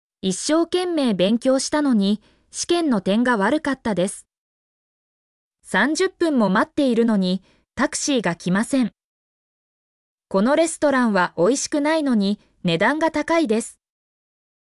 mp3-output-ttsfreedotcom-26_xVxP15ow.mp3